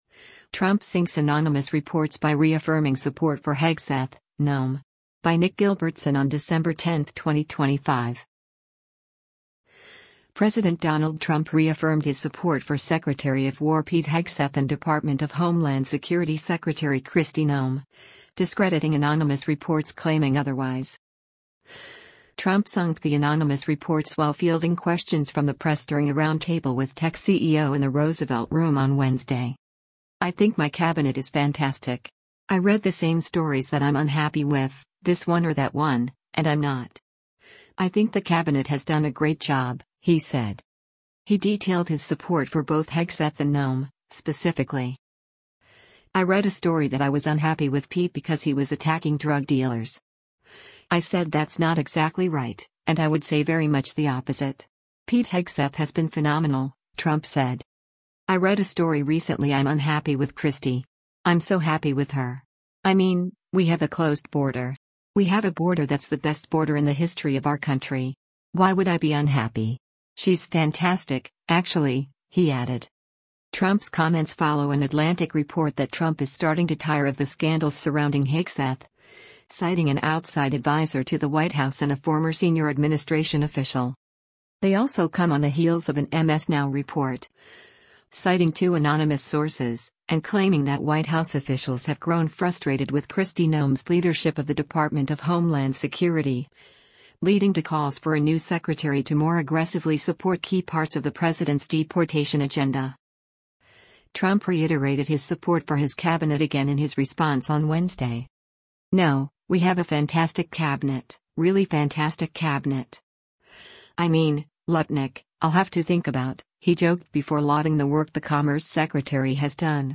Trump sunk the anonymous reports while fielding questions from the press during a roundtable with tech CEO in the Roosevelt Room on Wednesday.